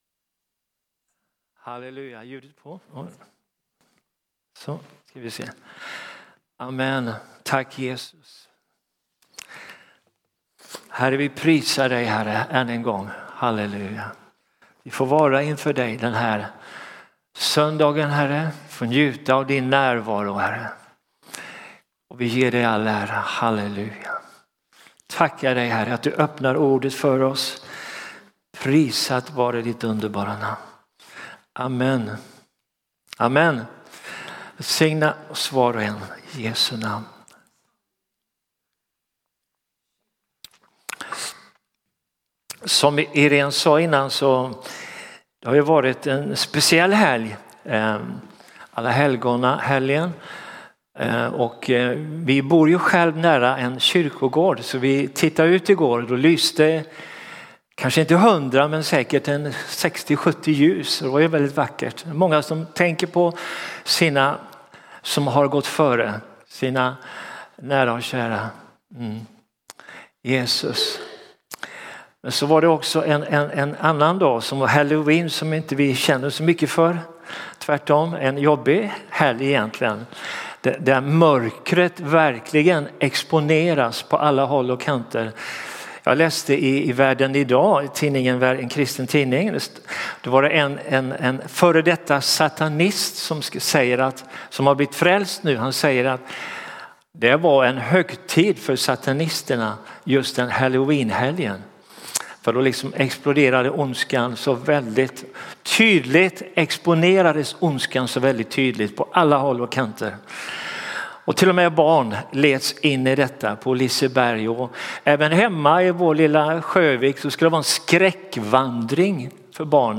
Pingstkyrkan Borås måndag 08 december 2025